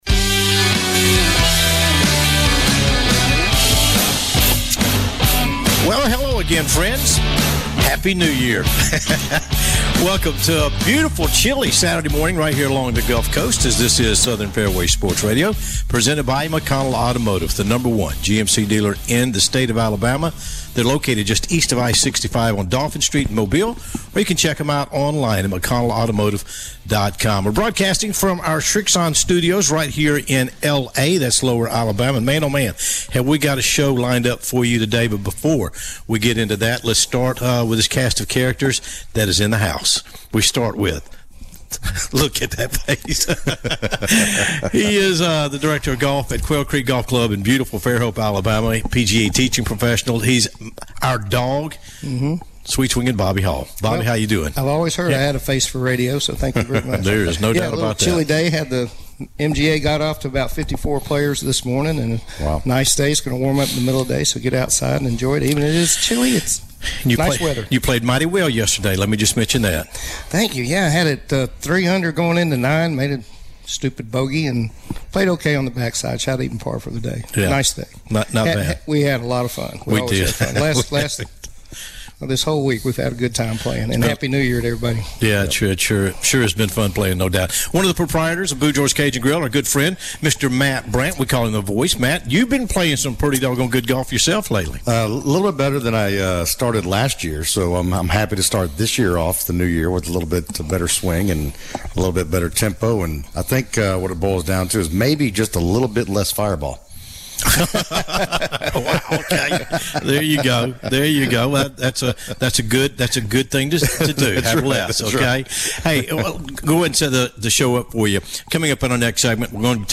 (SFSRS) Southern Fairways Sports Rdio Show 1.4.2025 w/guest Hall Of Famer John Smoltz